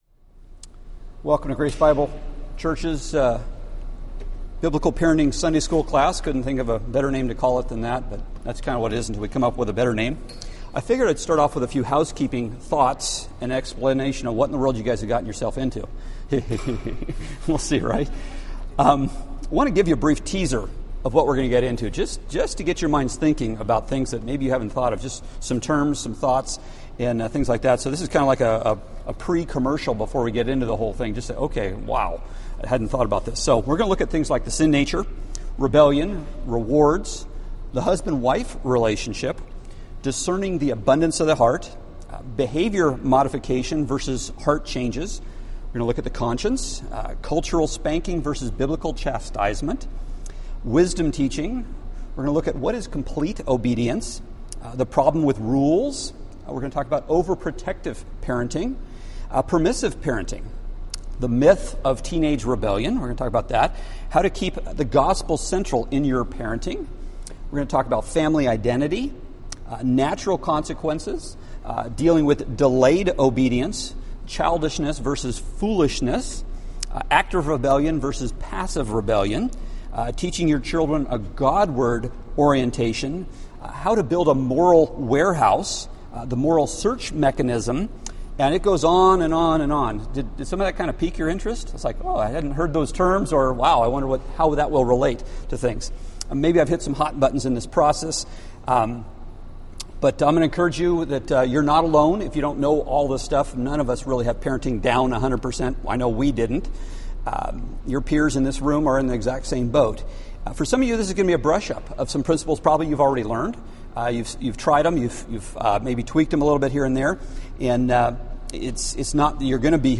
Date: Sep 13, 2015 Series: Biblical Parenting (2015) Grouping: Sunday School (Adult) More: Download MP3